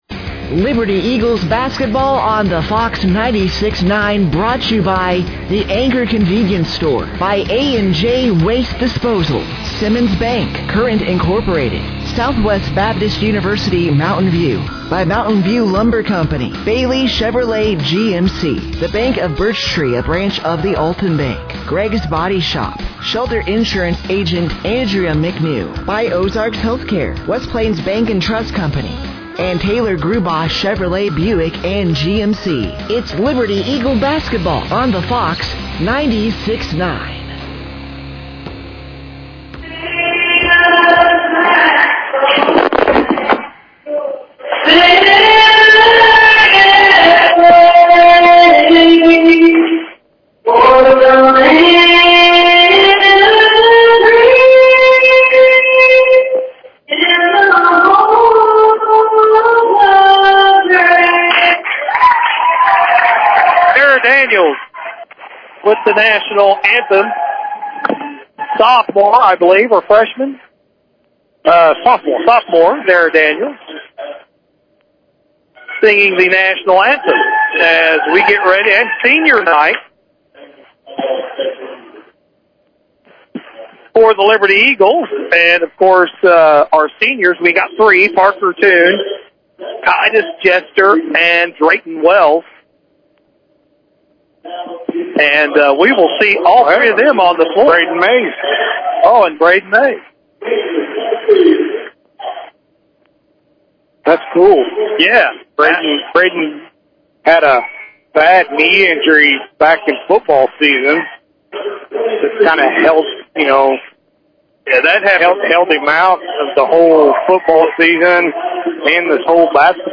Liberty comes back huge on senior night, 77-74 over The Salem Tigers
On Tuesday, February 17th, 2026; The Mtn-View Liberty Eagles Basketball Team hosted the Salem Tigers for senior night.